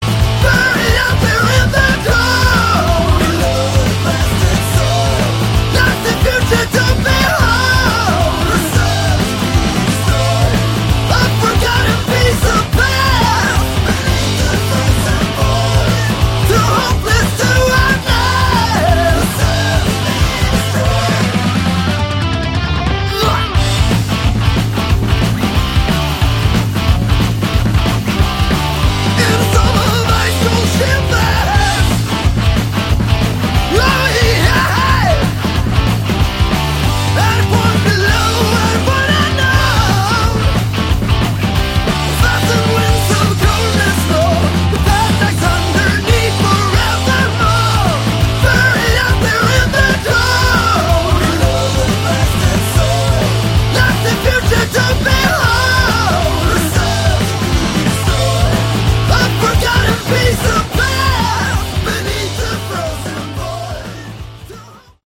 Category: Hard Rock
drums
guitars
vocals, bass